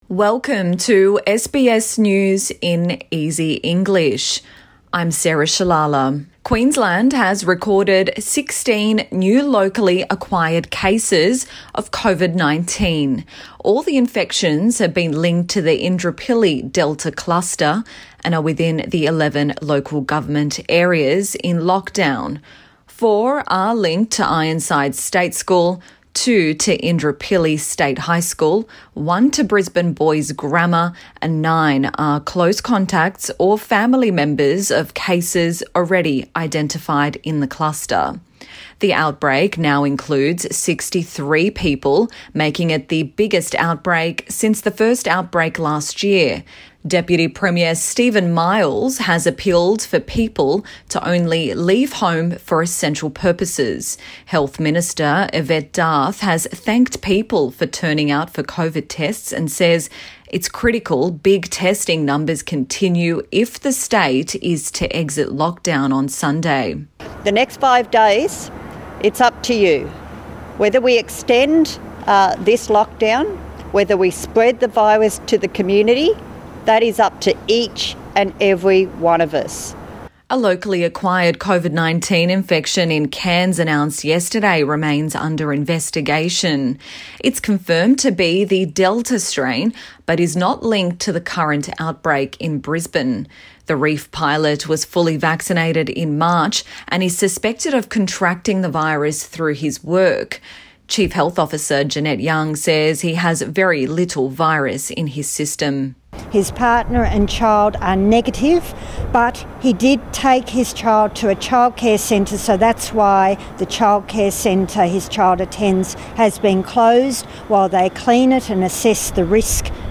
A daily 5 minute news wrap for English learners.